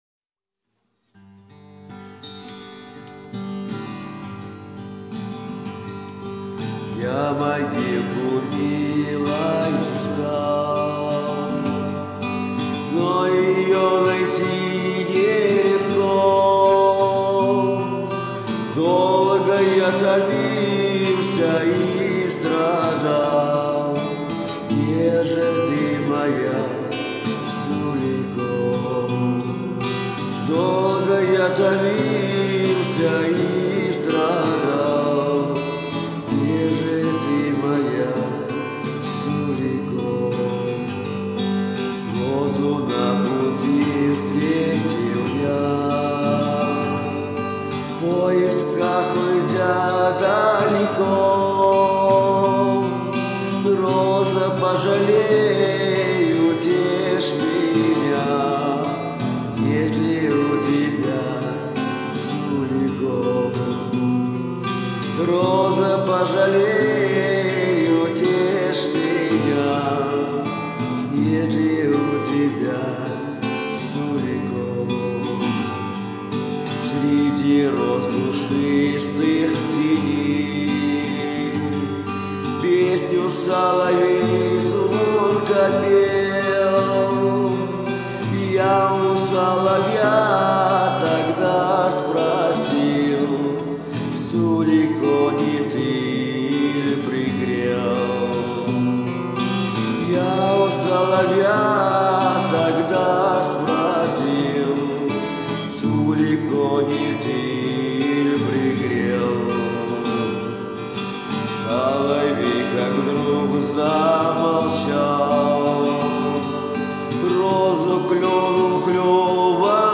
Ну эта песня народная :/